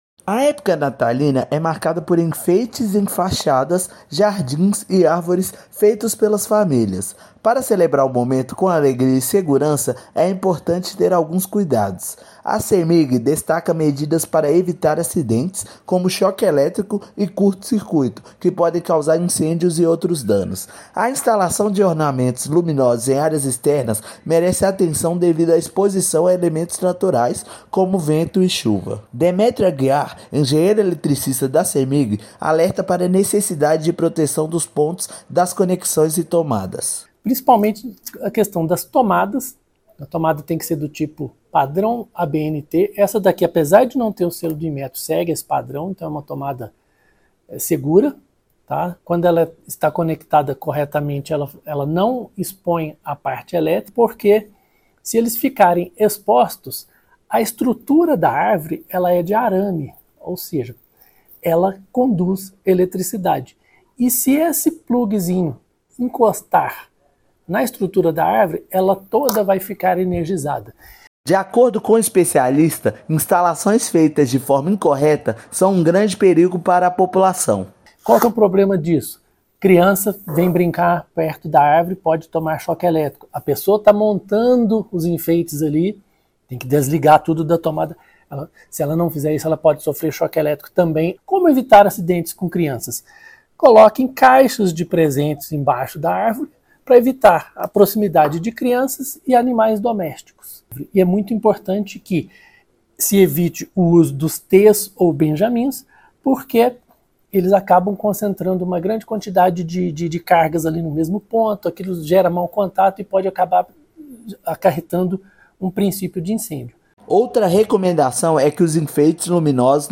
População deve redobrar atenção para evitar imprevistos envolvendo energia elétrica. Ouça matéria de rádio.